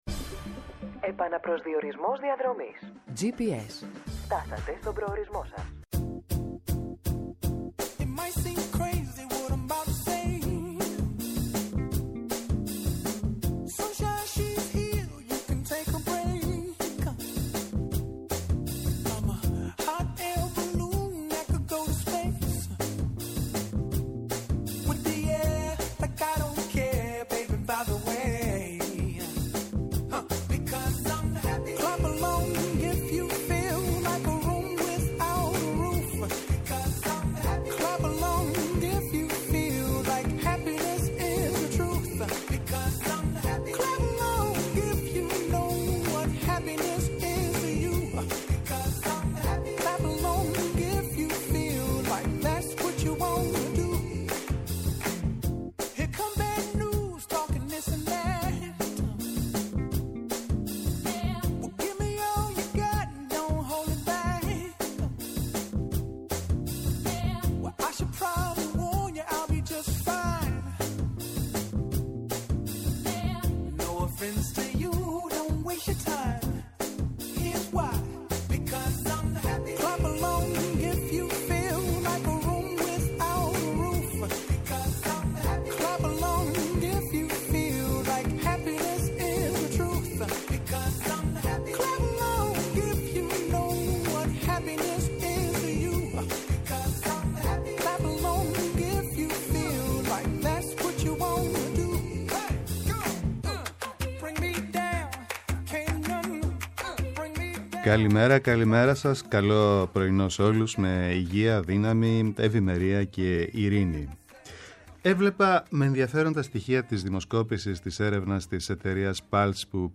μέσα από αποκαλυπτικές συνεντεύξεις και πλούσιο ρεπορτάζ